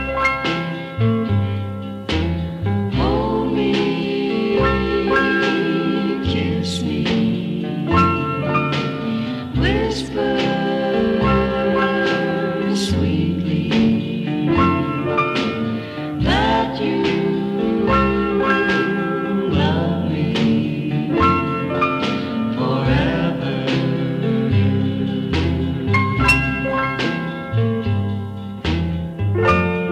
# Doo Wop